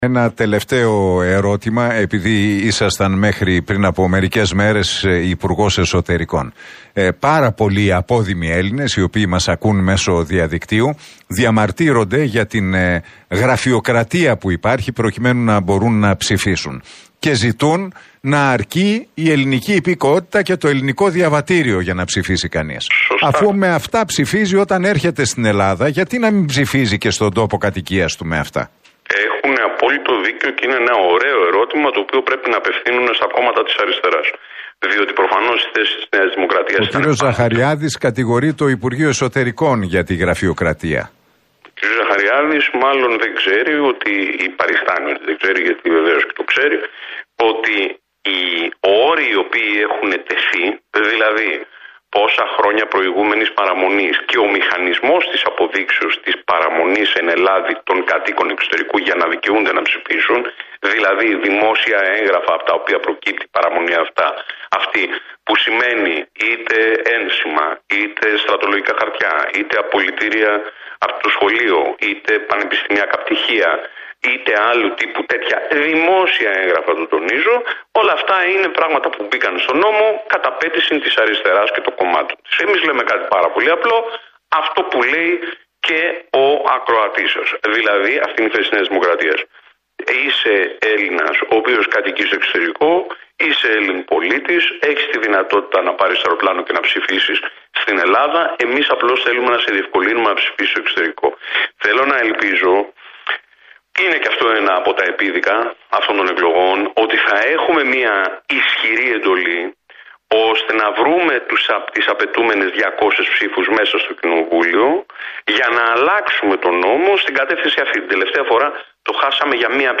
Ο πρώην υπουργός Εσωτερικών Μάκης Βορίδης κλήθηκε να τοποθετηθεί για το ίδιο θέμα στην εκπομπή του Νίκου Χατζηνικολάου.